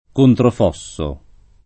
vai all'elenco alfabetico delle voci ingrandisci il carattere 100% rimpicciolisci il carattere stampa invia tramite posta elettronica codividi su Facebook controfosso [ kontrof 0SS o ] o contraffosso [ kontraff 0SS o ] s. m.